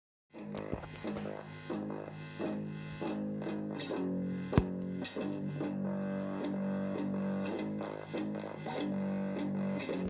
Im verzerrten Kanal gibt es vor allem beim Palm Mute komische Geräusche. Sind das die Röhren?